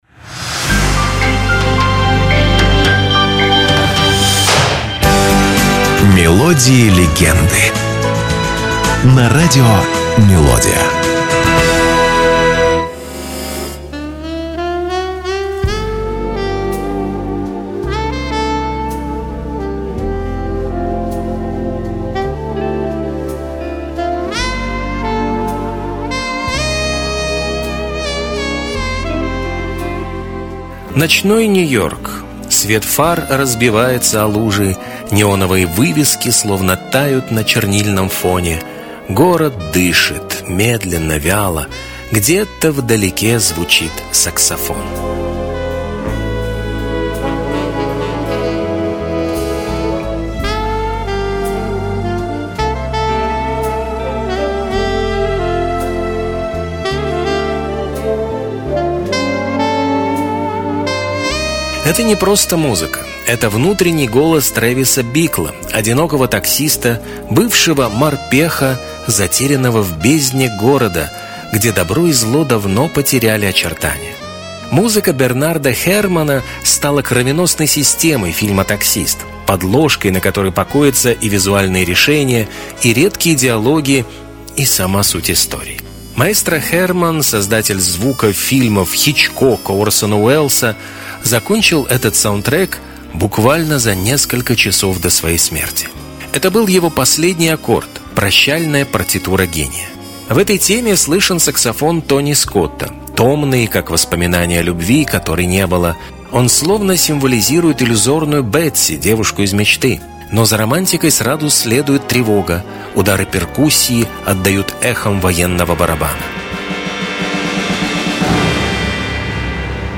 Это короткие музыкальные рассказы с душевным настроением, атмосферой ностальгии и лёгкой интригой. Вы услышите песни, которые звучали десятилетиями и, возможно, впервые узнаете, что стоит за этими знакомыми нотами.